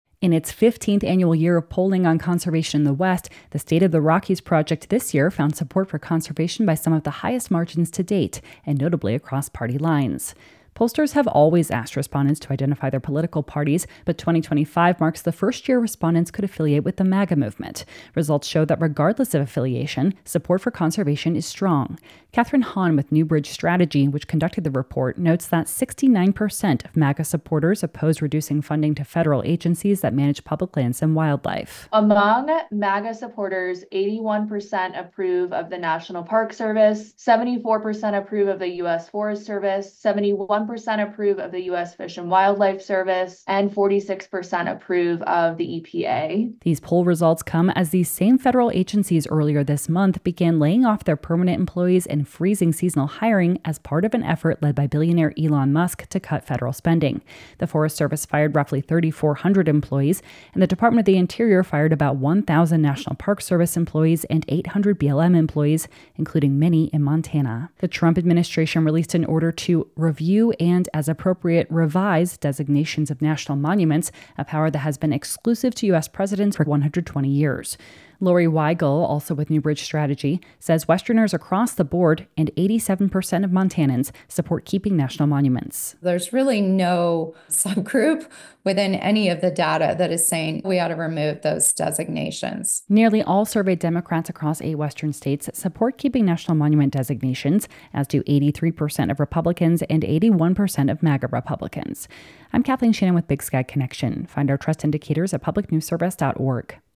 Comments by two pollsters with New Bridge Strategy